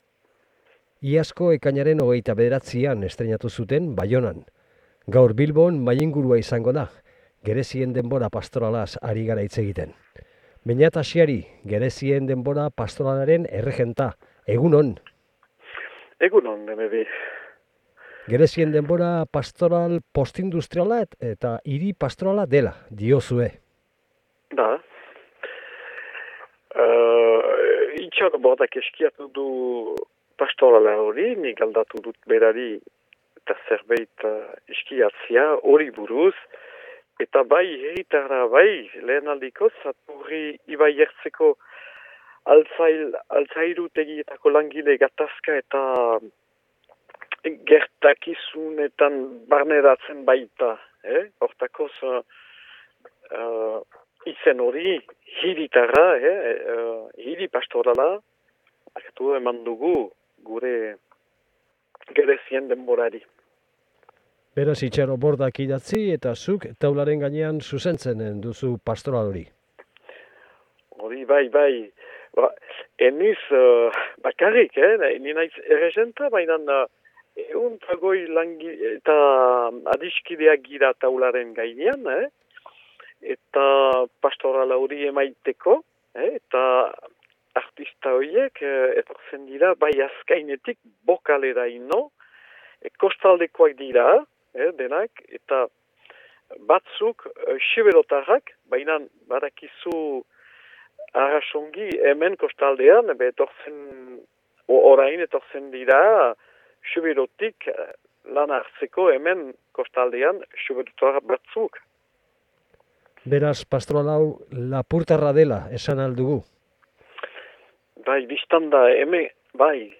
IBAIZABAL: “Gerezien denbora” pastorala eta solasaldia Beñat Achiaryrekin